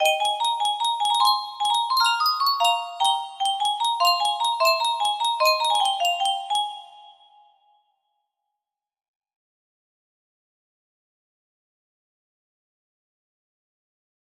Desperate despair music box melody
Full range 60